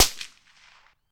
sounds_rifle_small_fire_01.ogg